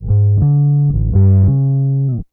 BASS 20.wav